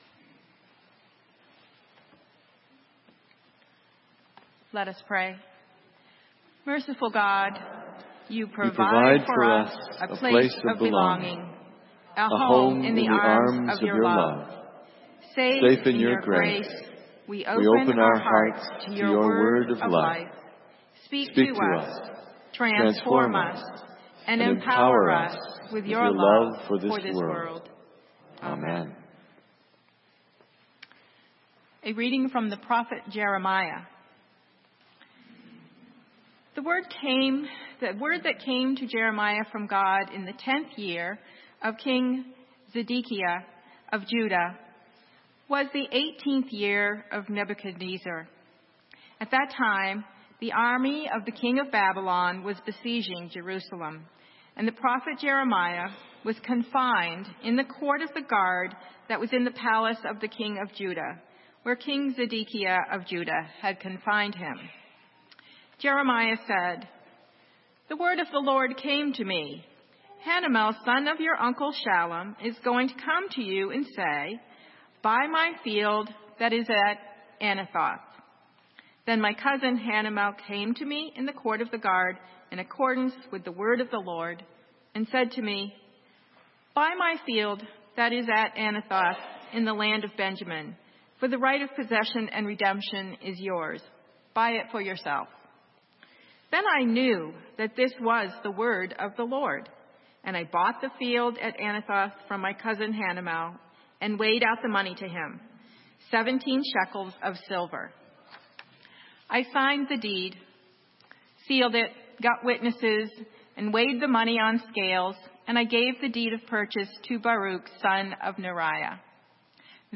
Sermon:Open the gate - St. Matthews United Methodist Church